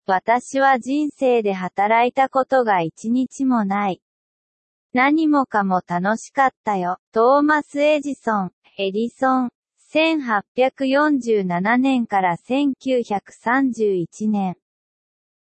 (Google Translateのエンジンを使用した、Sound of Textによるテキスト読み上げ)